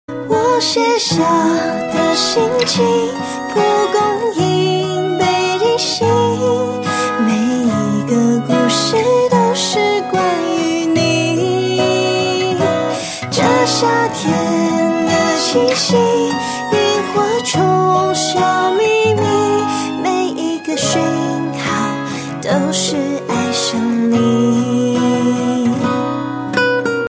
M4R铃声, MP3铃声, 华语歌曲 37 首发日期：2018-05-14 12:23 星期一